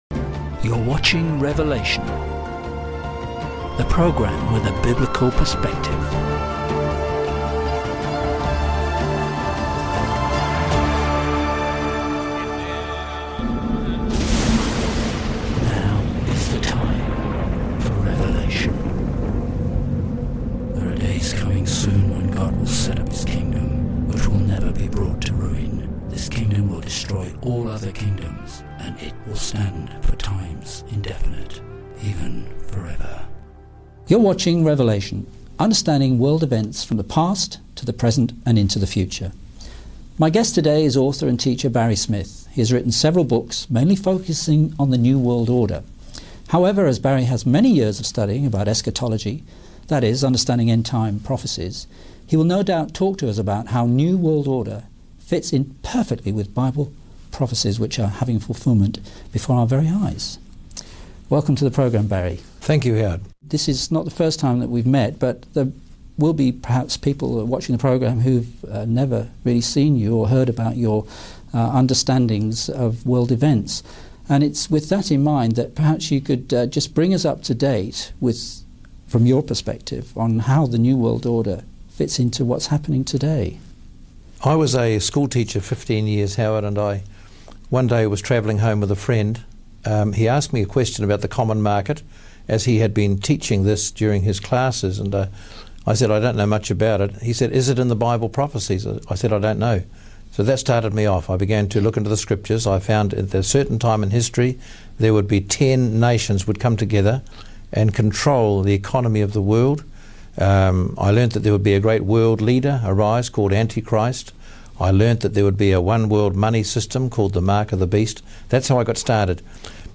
LAST INTERVIEW